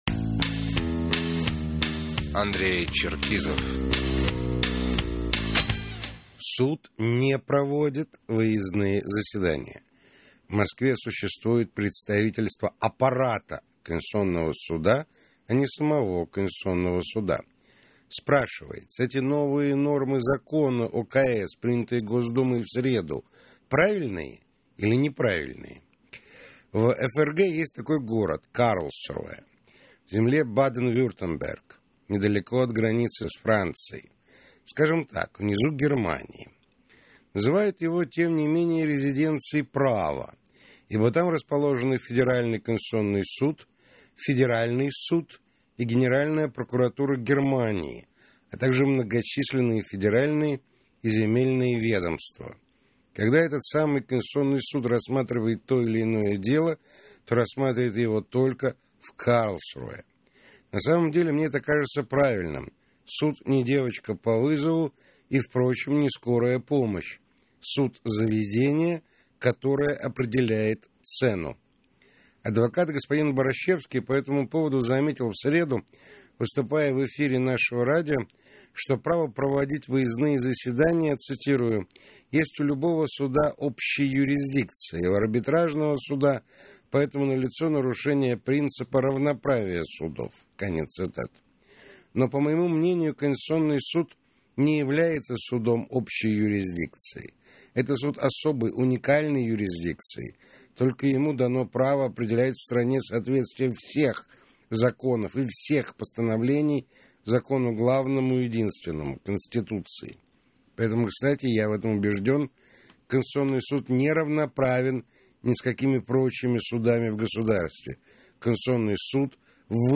Комментарий политического обозревателя радиостанции "Эхо Москвы" Андрея Черкизова - Реплика Черкизова - 2006-12-20